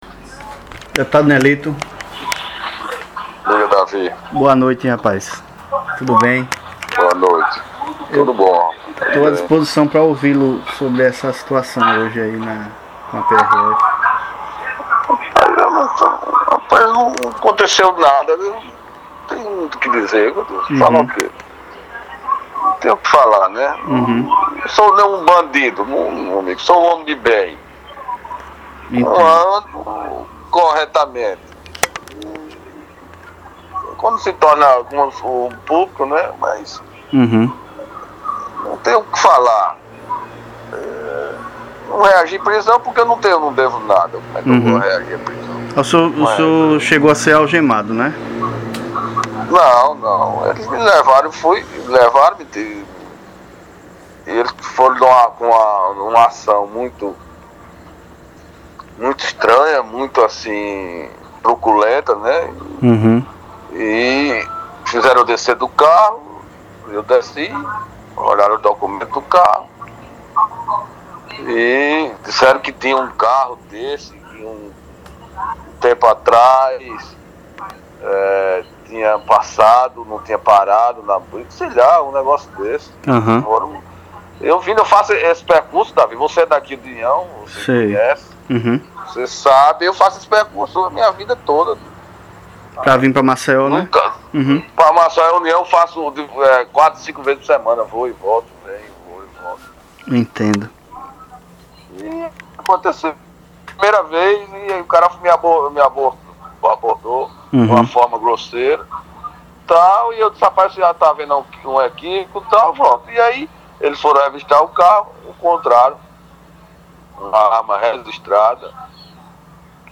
Ouça a entrevista concedida pelo ex-deputado Nelito ao Diário do Poder: